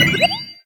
pgs/Assets/Audio/Collectibles_Items_Powerup/collect_item_25.wav at master
collect_item_25.wav